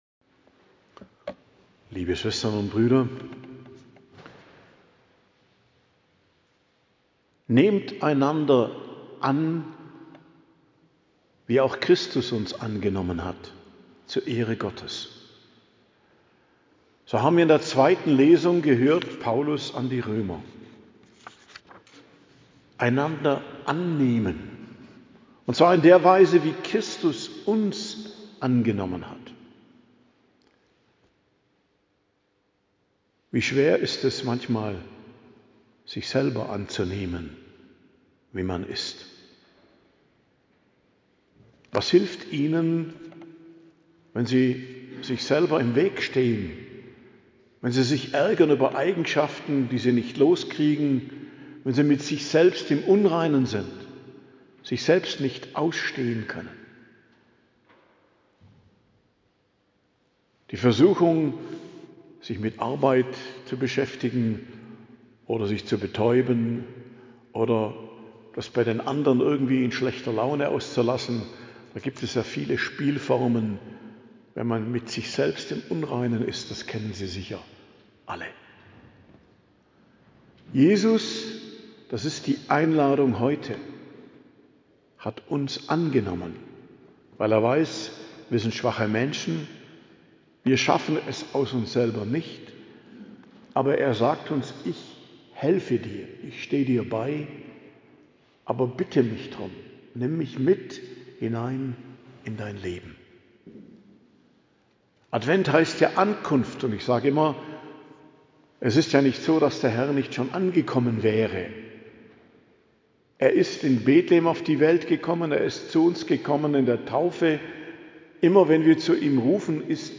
Predigt zum 2. Adventssonntag, 7.12.2025 ~ Geistliches Zentrum Kloster Heiligkreuztal Podcast